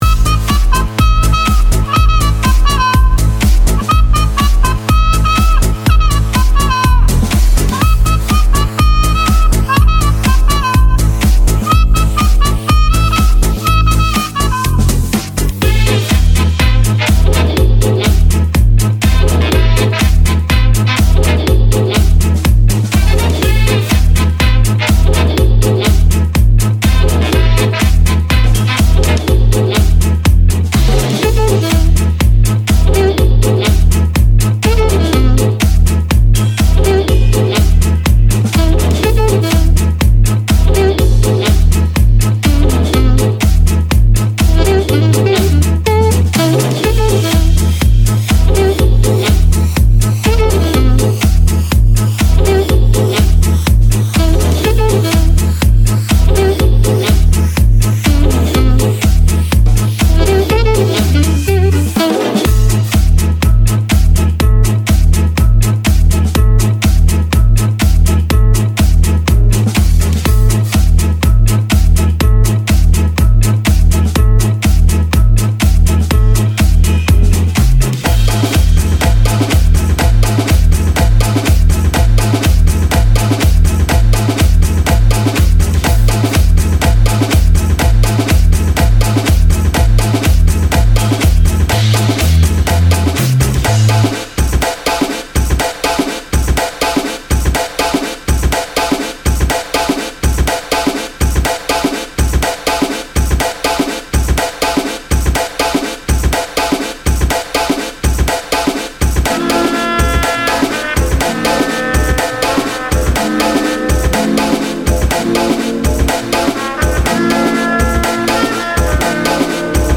Electro Swing, Jazzy House, Funky Beats DJ for Hire